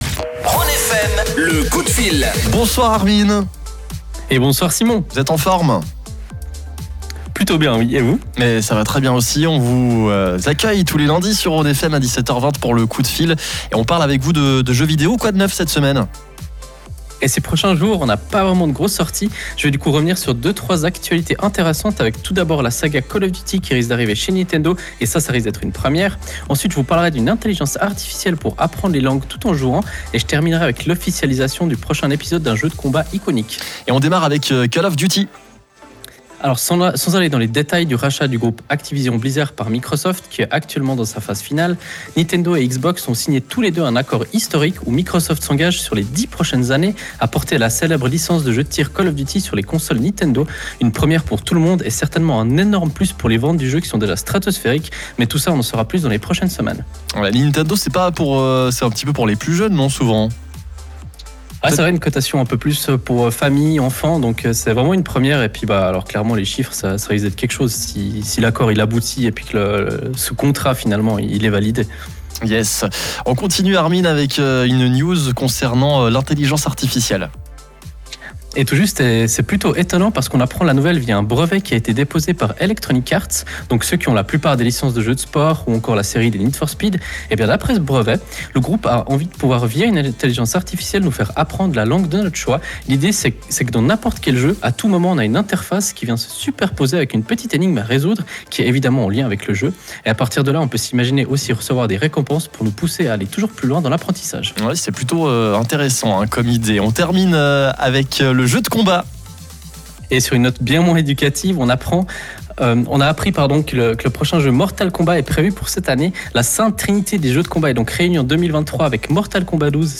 Vous pouvez retrouver le direct de la chronique via le lien qui se trouve juste en dessus.